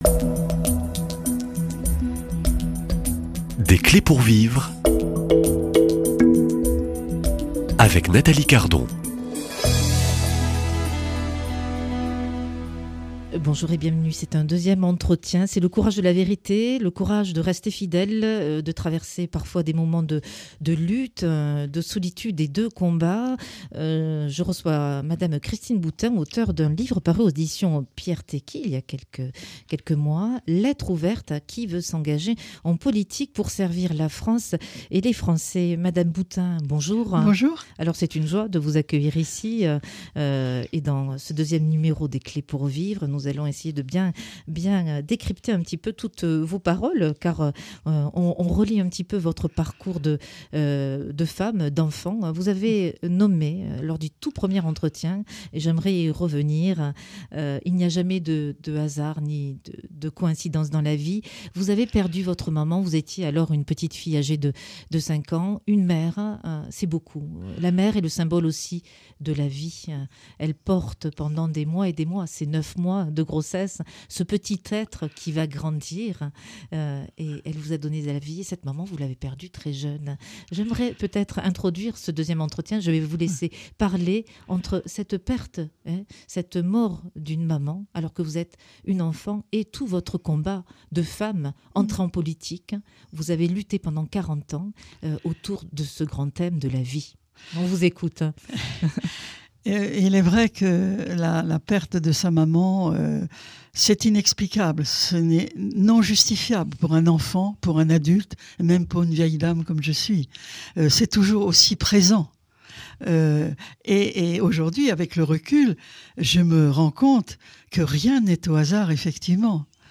Dans ce deuxième entretien, Christine Boutin revient sur les heures de solitude, de combat, mais aussi de fidélité.